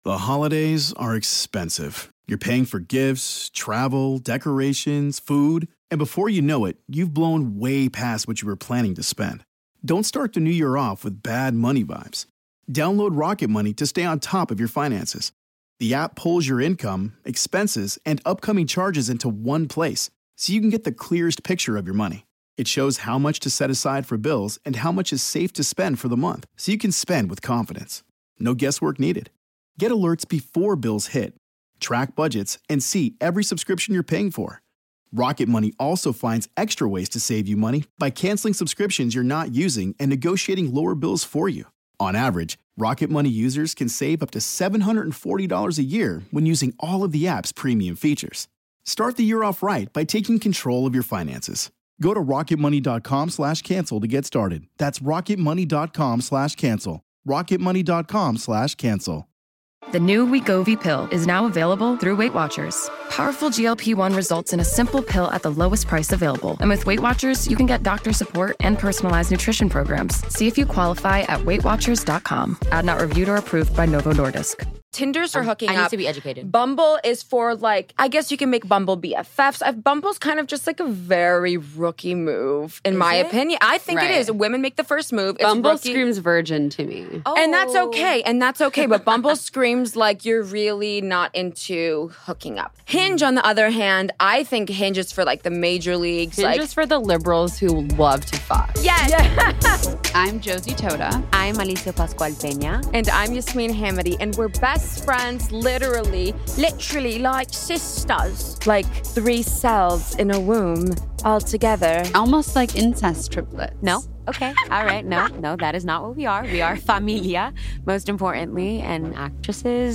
three young actresses, disruptors, and best friends as they navigate the issues that affect our lives